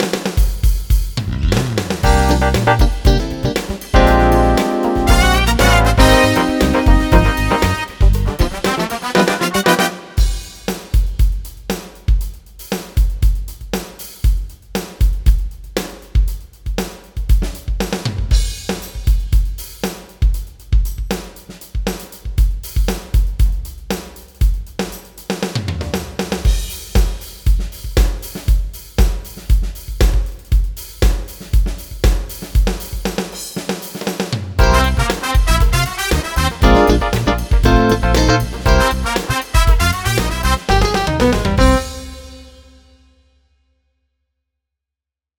itt meg egy másik dobbal: